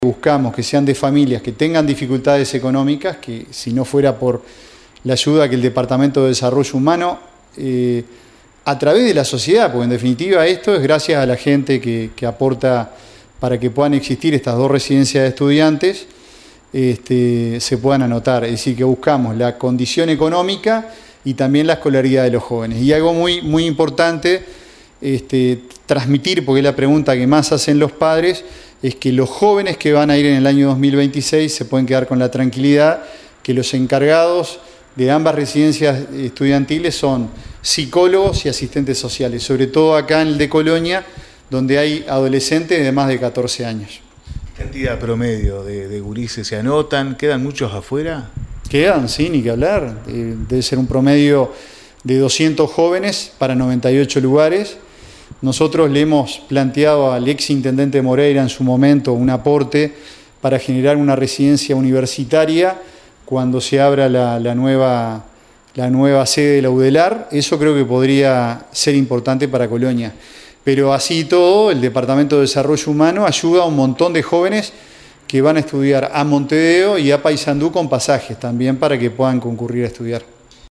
Sobre el tema, dialogamos con el director de Desarrollo Humano, Ricardo Planchón.